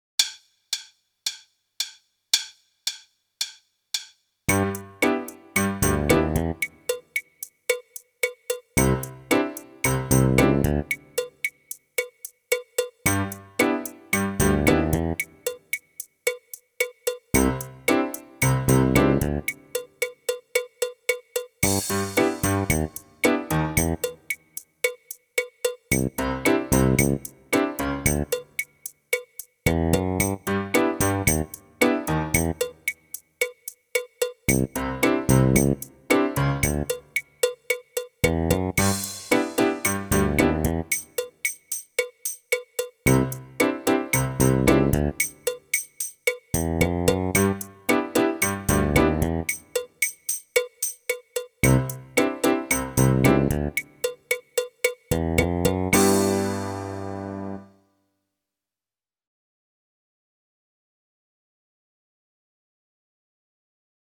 Meespeel CD
27. De paradiddle